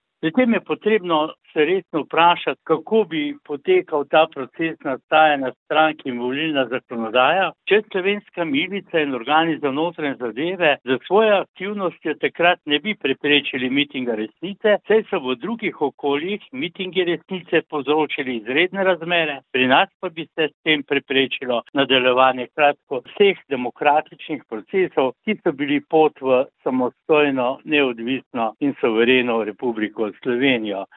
V večnamenski dvorani Lopan v Mislinji je včeraj potekal spominski dan Združenja Sever.